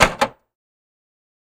Time Punch Clock | Sneak On The Lot